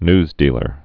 (nzdēlər, nyz-)